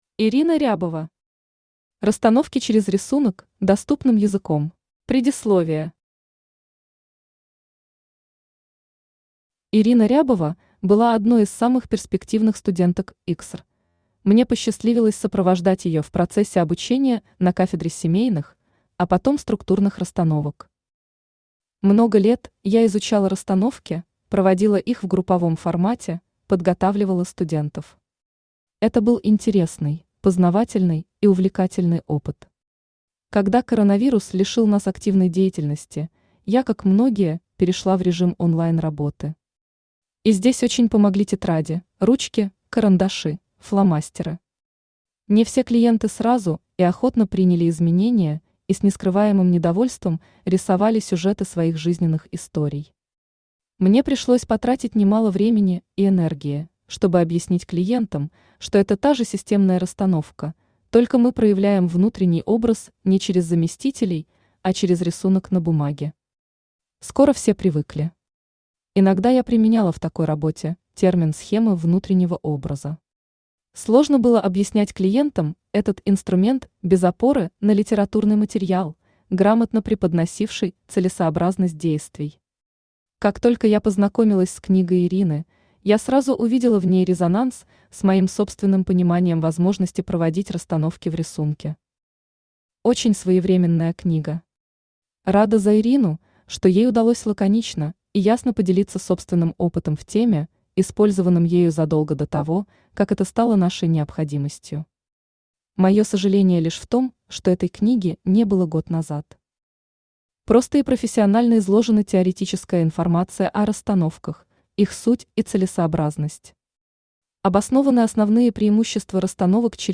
Aудиокнига Расстановки через рисунок – доступным языком Автор Ирина Михайловна Рябова Читает аудиокнигу Авточтец ЛитРес.